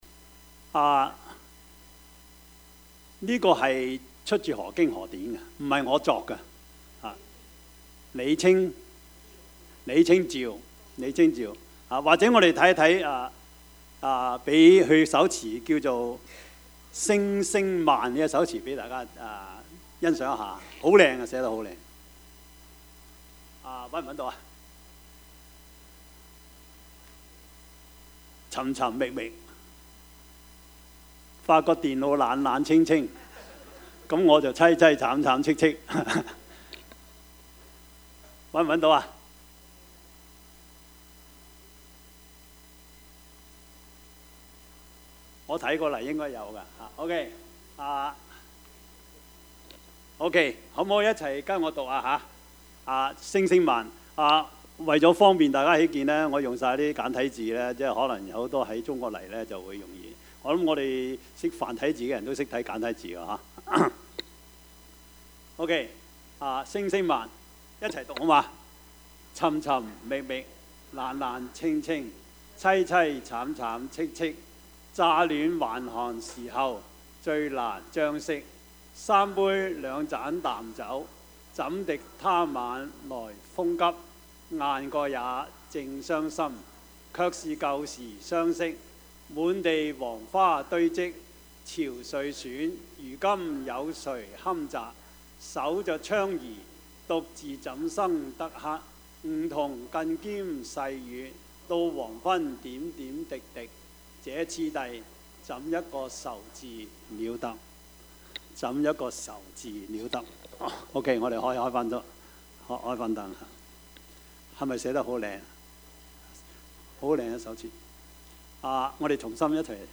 Service Type: 主日崇拜
Topics: 主日證道 « 天賜我分必要用 我為錢狂?